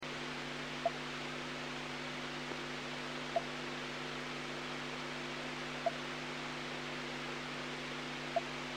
165.025 МГц - метроном в С-Петербурге